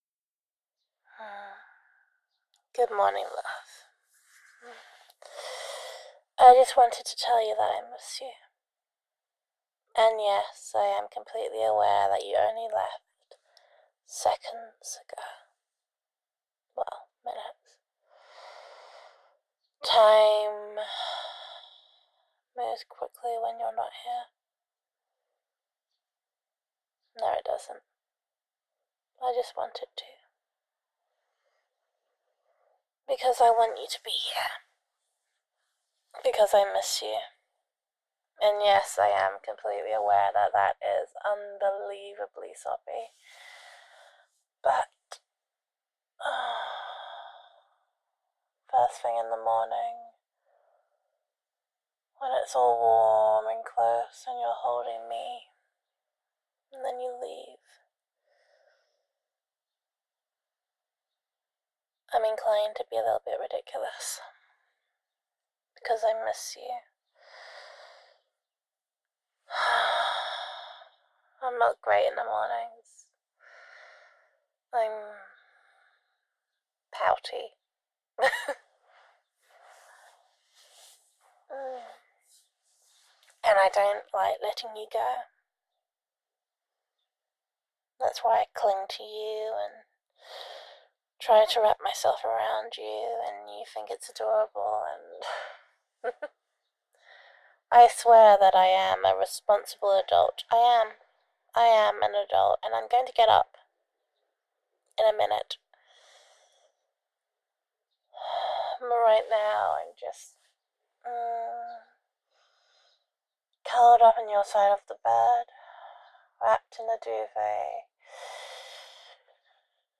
[F4A] Good Morning, Love [I Miss You][Even Though You Only Just Left][Unbelievably Soppy][Pouty][Telephone Eq][Gender Neutral][Girlfriend Voicemail]